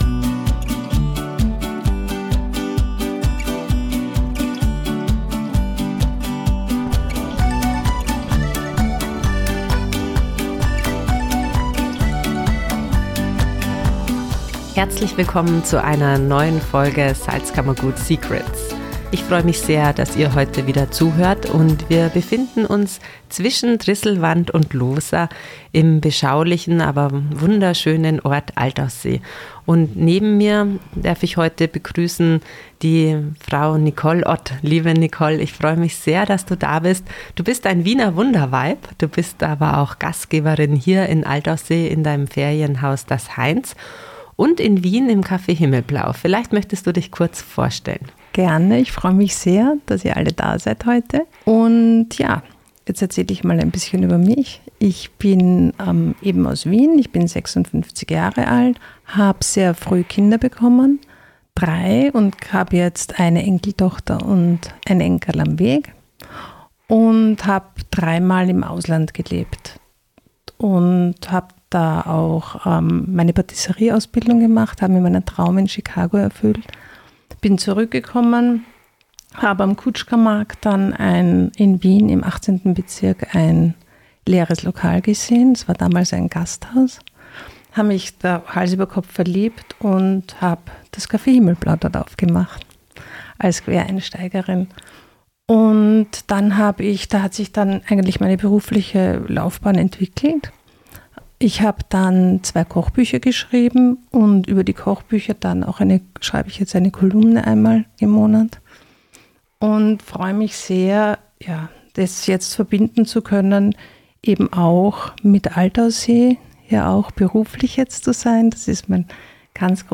Ein wahrlich köstlicher Podcast-Termin heute im bestens bekannten Altaussee.